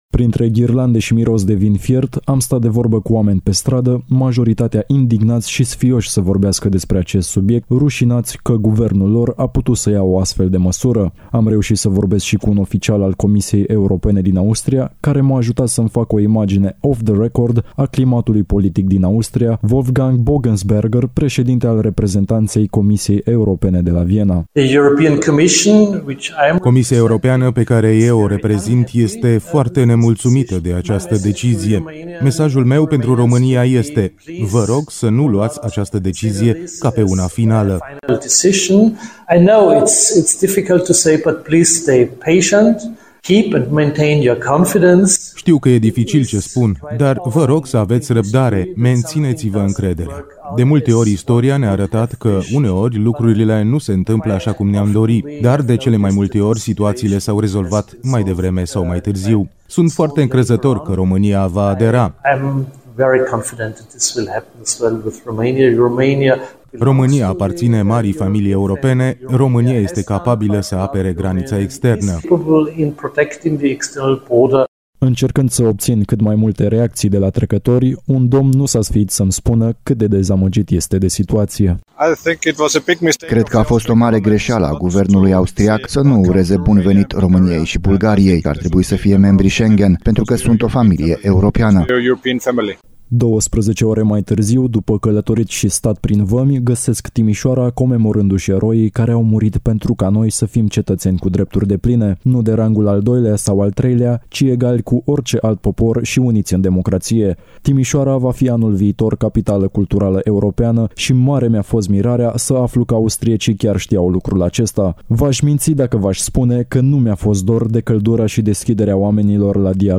Din Mica Vienă spre Viena, pe urmele eșecului aderării României la spațiul Schengen/ Reportaj
Care este opinia publică vizavi de acest subiect? Într-o paralelă dintre Mica Vienă și Viena.